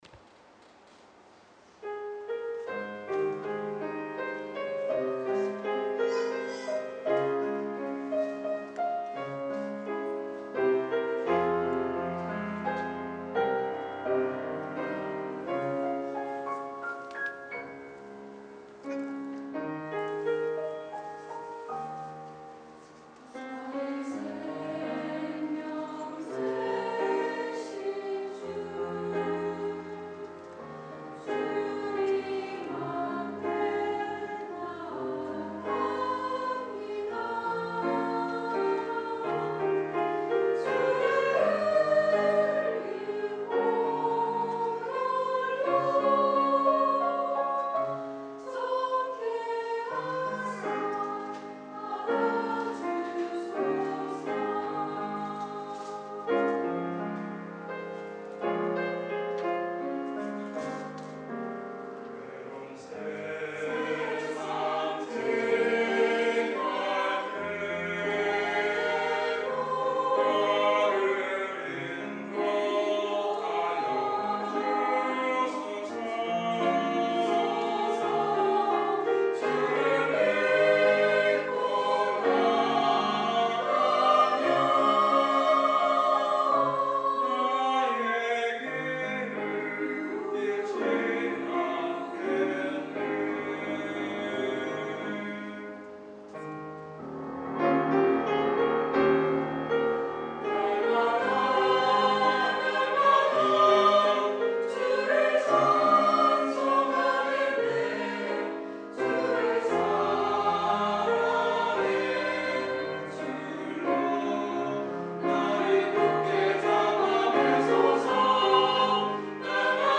This entry was posted in 주일찬양듣기.